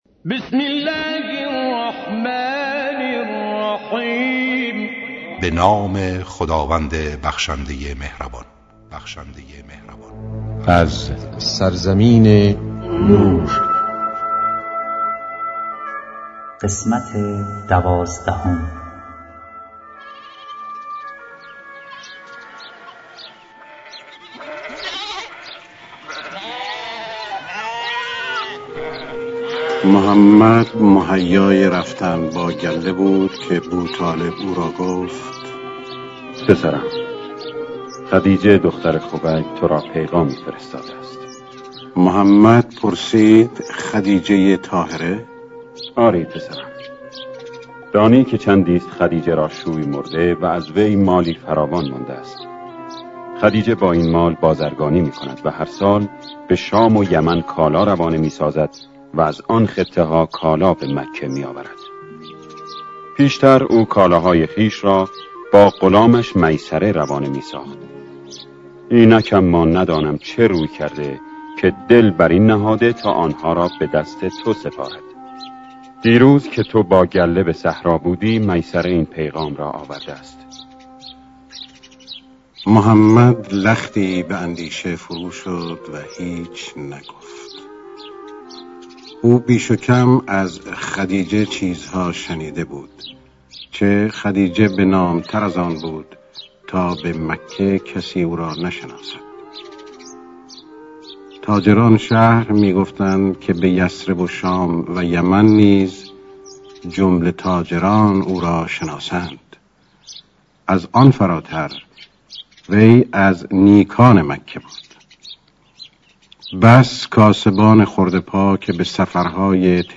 با اجرای مشهورترین صداپیشگان، با اصلاح و صداگذاری جدید
کتاب گویا